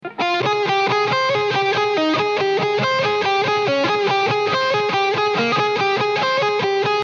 Да я просто сыграл на слух как помню по памяти.